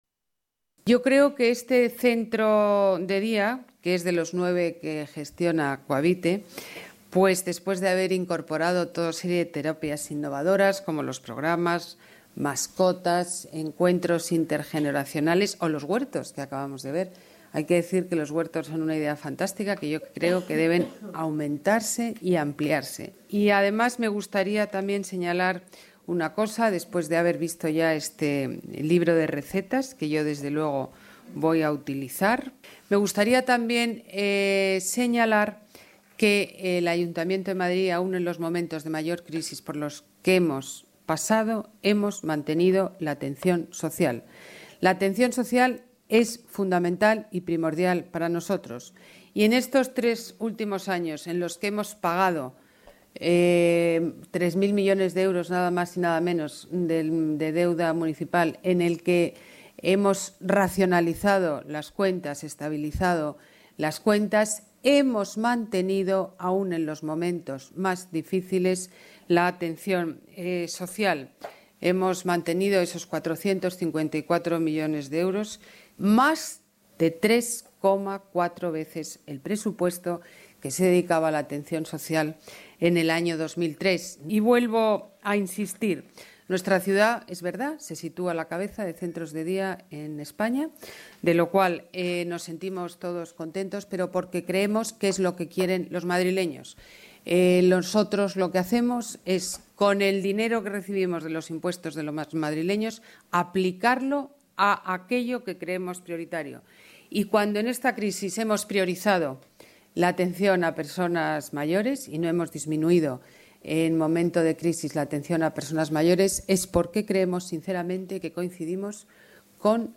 Nueva ventana:Declaraciones de Ana Botella: presentación libro usuarios centros Mayores